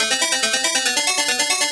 CS_FMArp C_140-A.wav